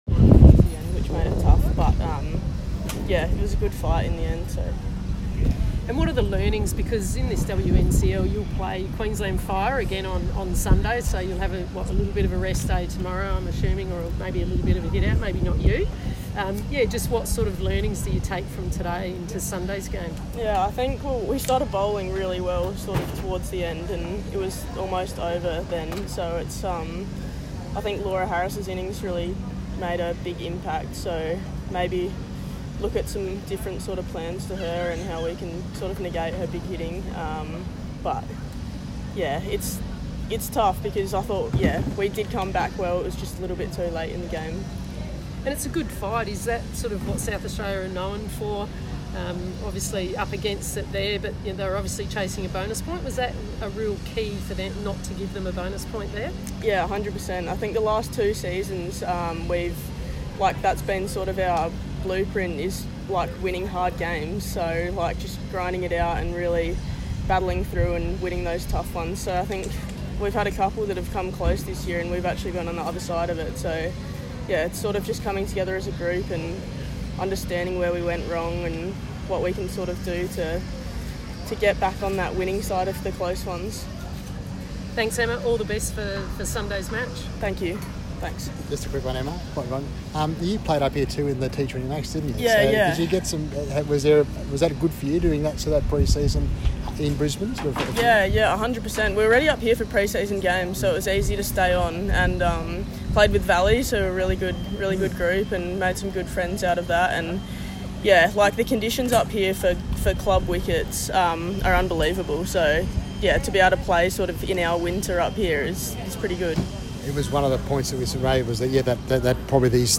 speaking post-game following their their three wicket loss to Qld at Allan Border Field in the WNCL.